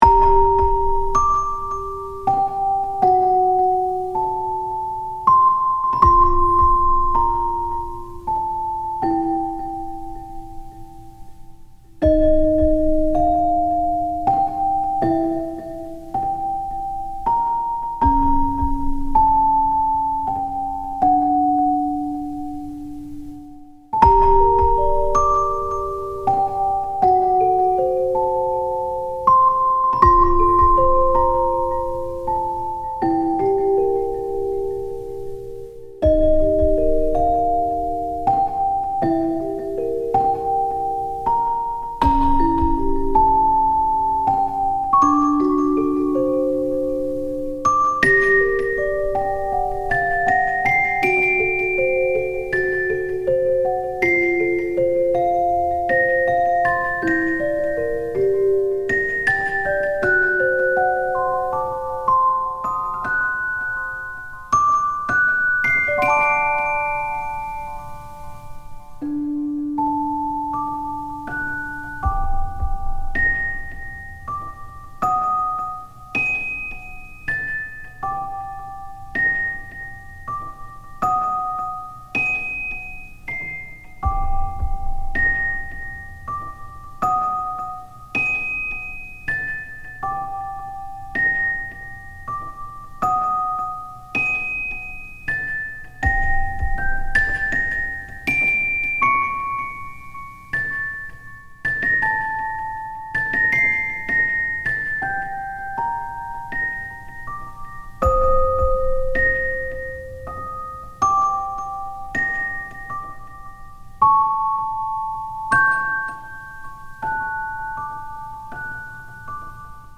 不吉な予感をさせる暗く切ないBGM